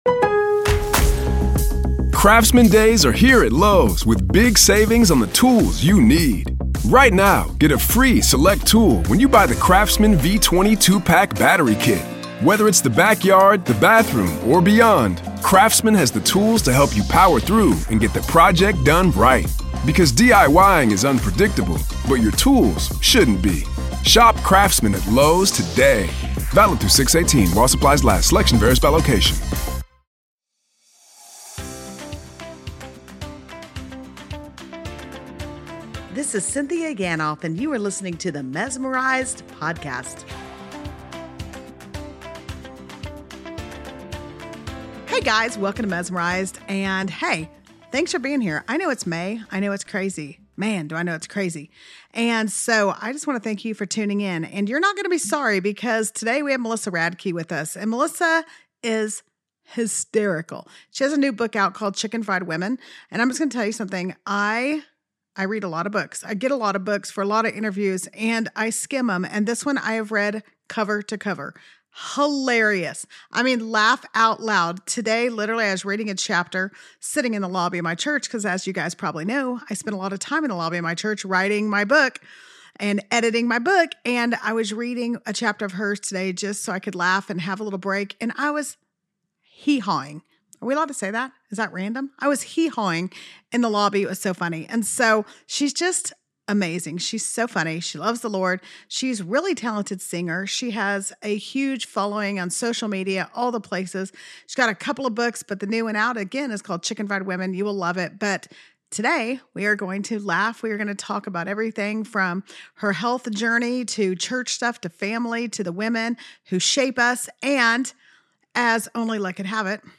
Today on MESSmerized, we get to hear from Pastor Louie Giglio (and you guys know how much I love Pastor Louie and the impact he’s had on my life since I was in college a million years ago). We have such a great conversation about the powerful truths in Psalm 84:11 and what it really means to walk in God’s grace, reflect His glory, and trust in His goodness—even when life feels really, really hard. Pastor Louie offers a fresh perspective on God’s grace, showing us it’s not just for salvation—it’s for the carpool lines, parent-teacher conferences, and every messy moment of life.